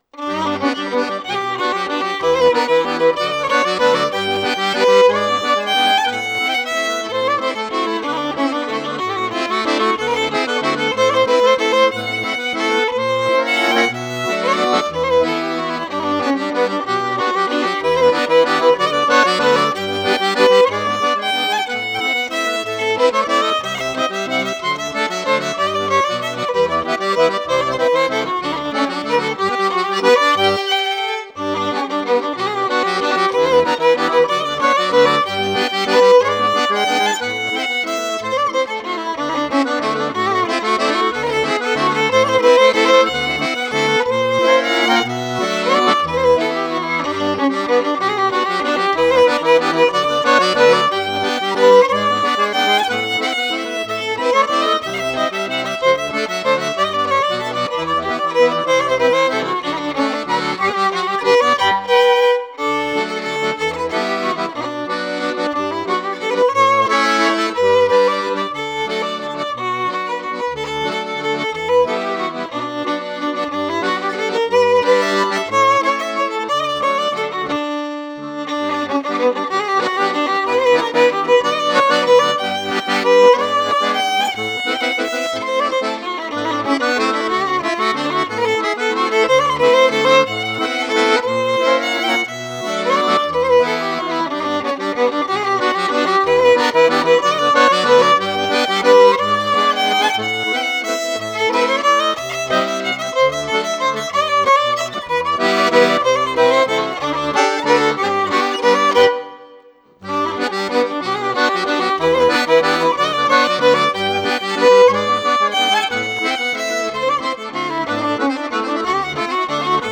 Musique à danser du Poitou